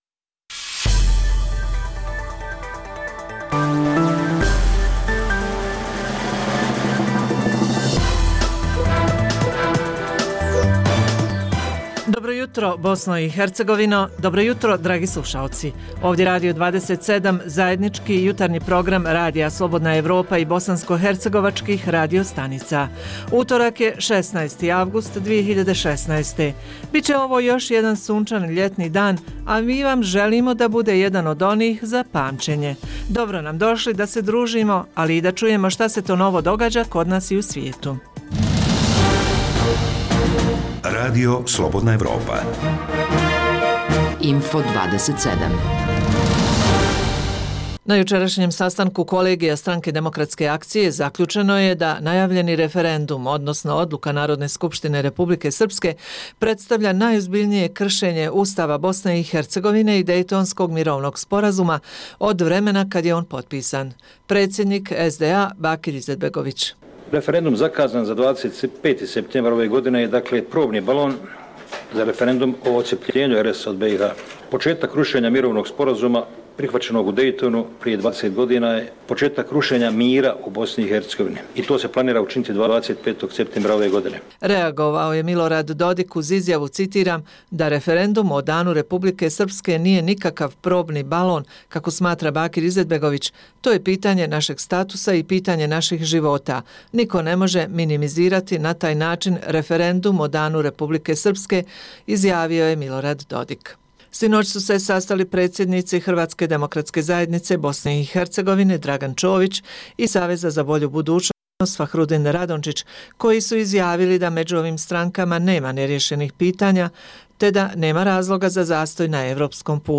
Jutarnji program za BiH koji se emituje uživo: - Peti dan Sarajevo Film Festivala - Sportska nadmetanja i rezultati na Ljetnim Olimpijskim igrama - Hoće li politika podijeliti srednjoškolce u Jajcu po nacionalnoj pripadnosti? - Bijeljina bez turističkog suvenira - Ljeto u gradu : Doboj, Jablanica, Prijedor i Brčko - Koje su novosti iz svijeta modernih tehnologija?
Redovni sadržaji jutarnjeg programa za BiH su i vijesti i muzika.